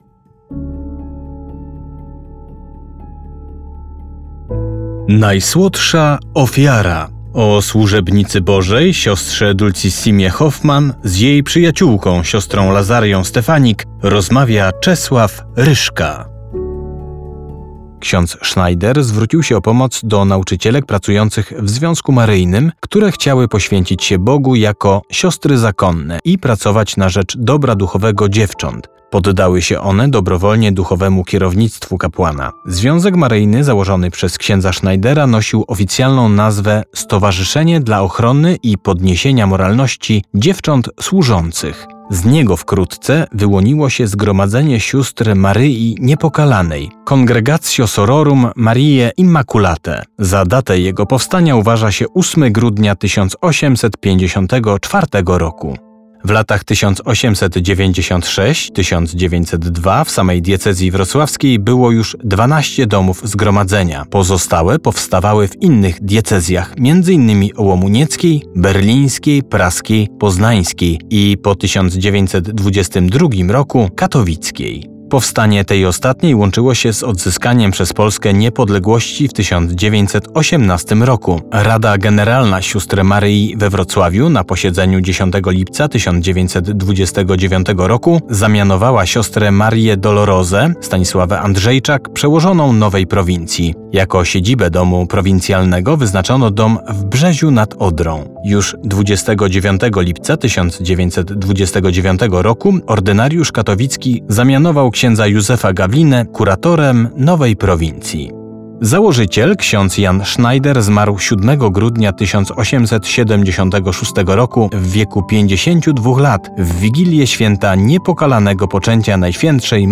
Propozycją duszpasterską Radia Rodzina na Wielki Post jest specjalny audiobook pt. „Dulcissima -Najsłodsza Ofiara”.